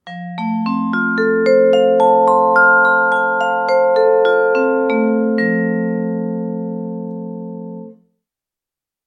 vibrafono.mp3